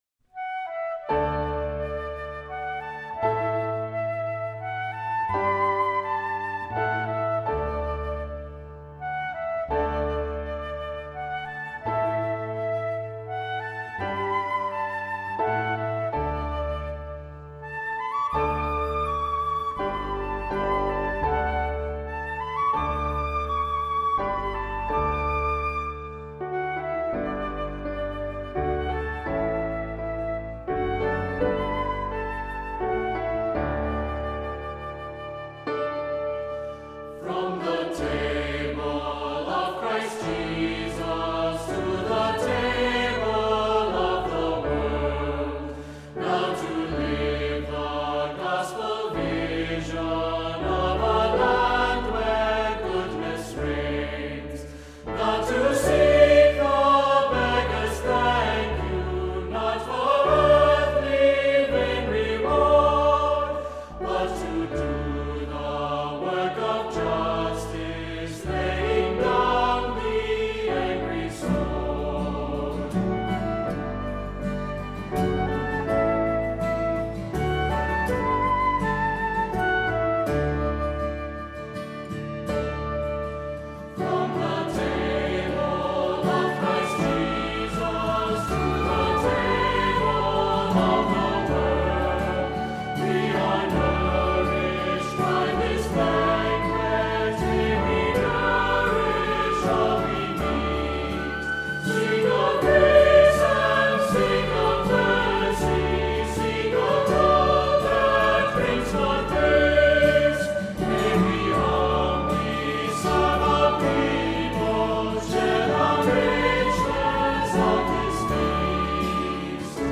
Accompaniment:      Keyboard, C Instrument
Music Category:      Christian